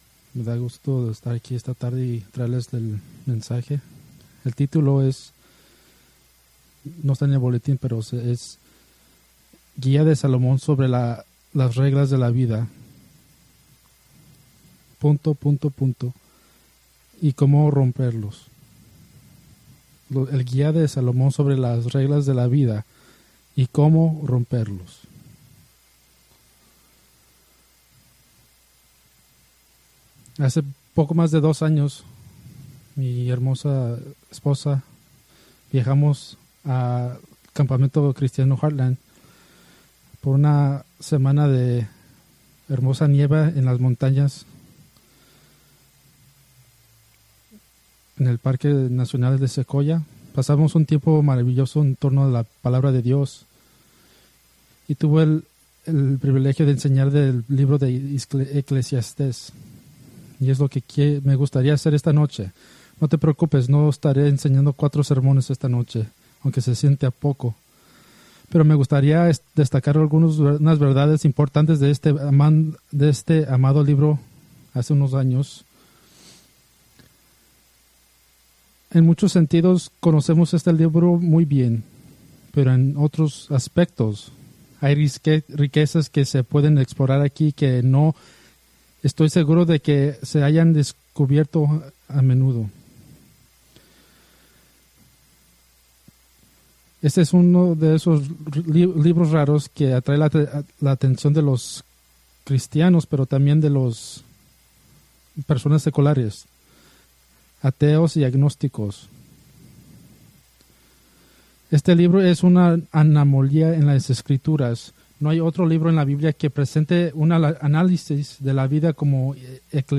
Preached March 9, 2025 from Ecclesiastes